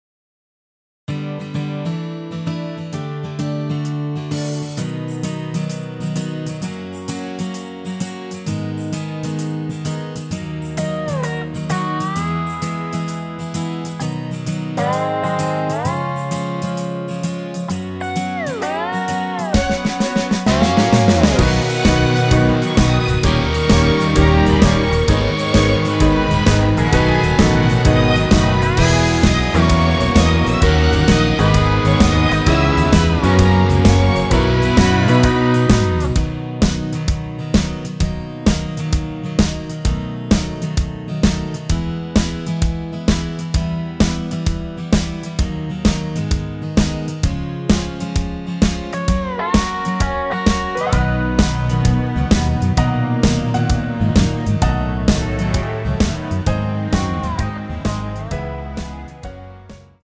전주 없는 곡이라 전주 2마디 만들어 놓았습니다.
엔딩이 페이드 아웃이라라이브 하시기 편하게 엔딩을 만들어 놓았습니다.
◈ 곡명 옆 (-1)은 반음 내림, (+1)은 반음 올림 입니다.
앞부분30초, 뒷부분30초씩 편집해서 올려 드리고 있습니다.